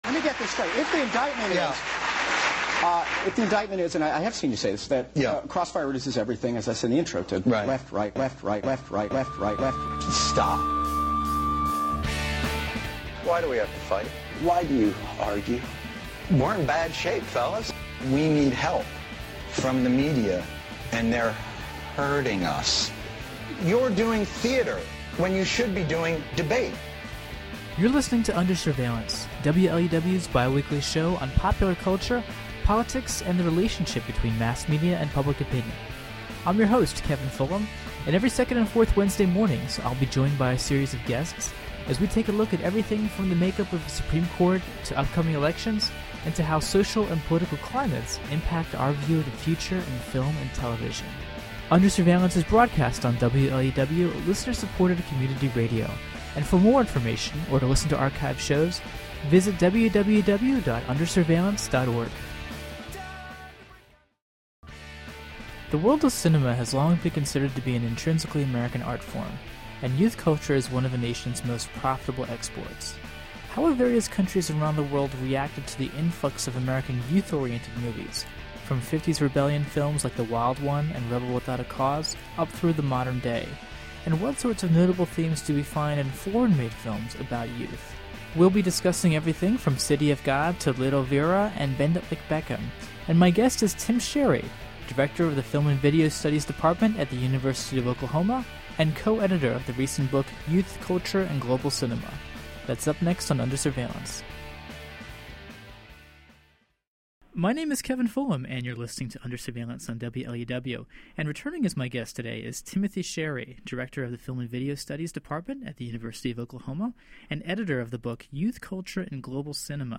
[Originally broadcast on WLUW’s Under Surveillance in October 2008.] https